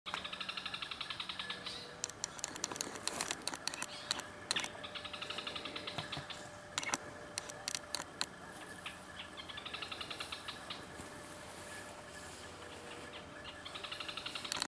Cisqueiro (Clibanornis dendrocolaptoides)
Nome em Inglês: Canebrake Groundcreeper
Localidade ou área protegida: Parque Provincial Araucaria
Condição: Selvagem
Certeza: Gravado Vocal
MVI_9219-tacuarero.mp3